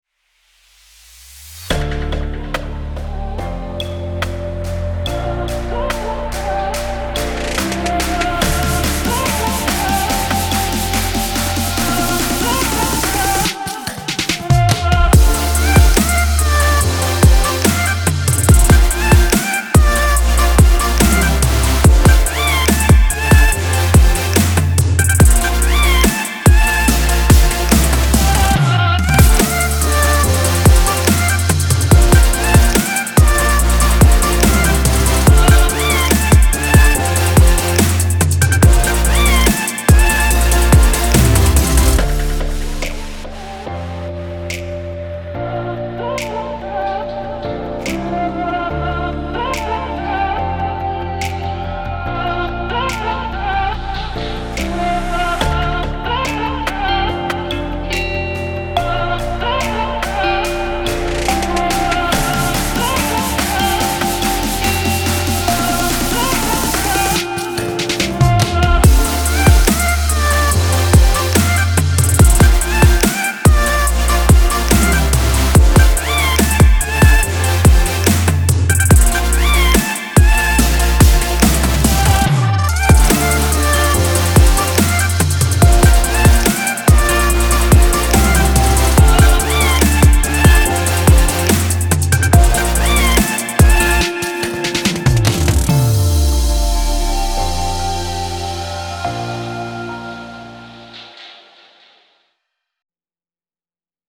Genres:Marketing / Instagram